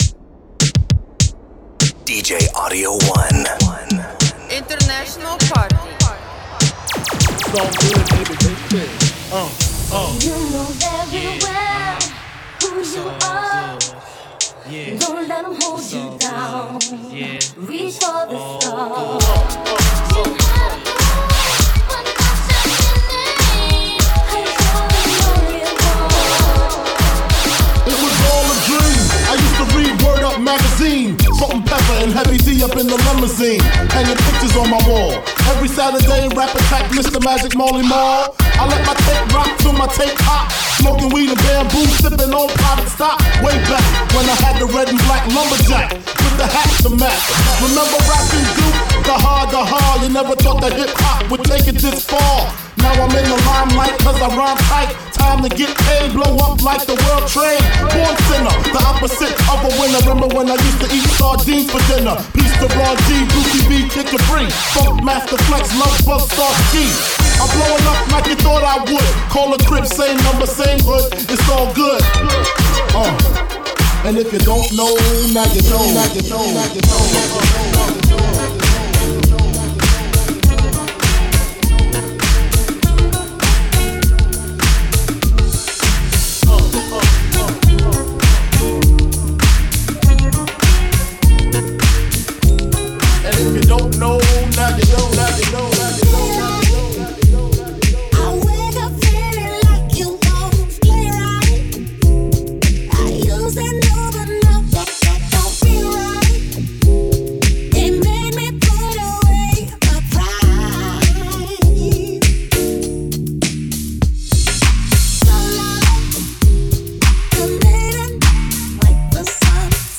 open-format, hip-hop and indie dance jams/remixes